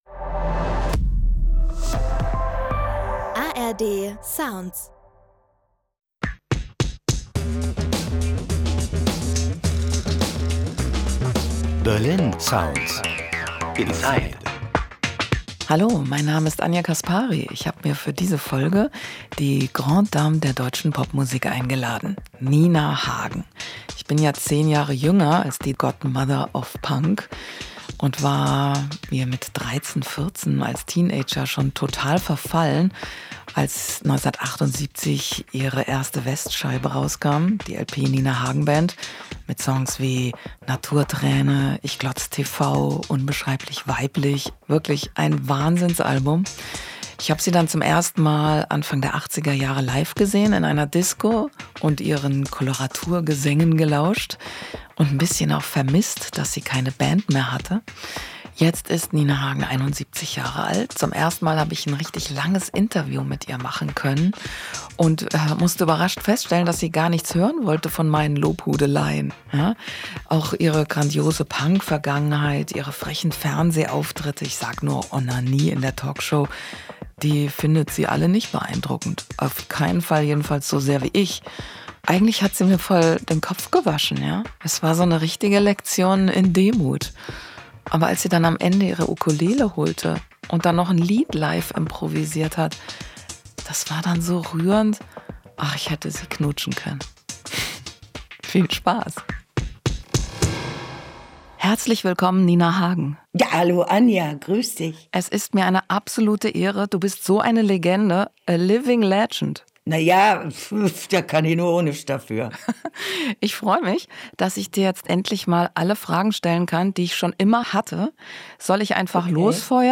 Nina Hagen blickt zurück auf ihre außergewöhnliche Karriere als Deutschlands "Godmother of Punk" ~ Berlin Sounds Inside – Der Musiktalk